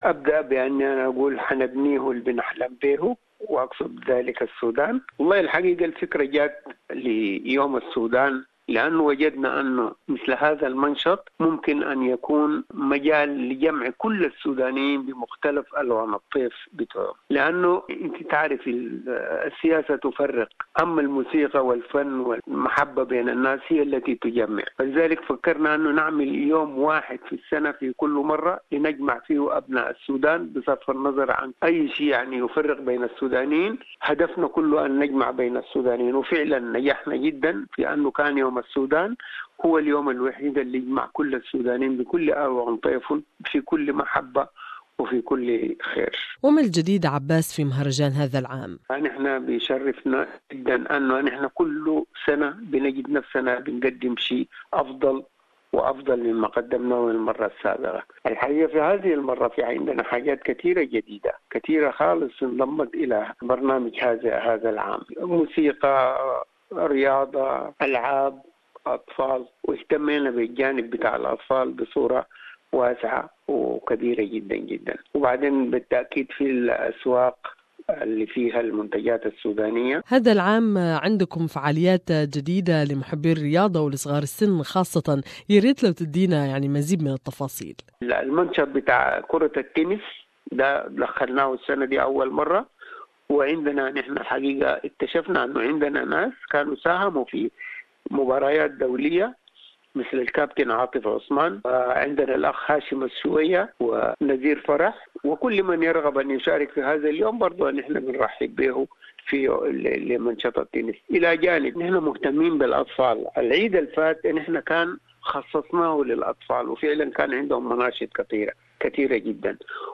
The Sudanese community in Sydney had enjoyed another great Sudan Festival day, to celebrate Sudanese culture and art. More in this interview with member of the organizing committee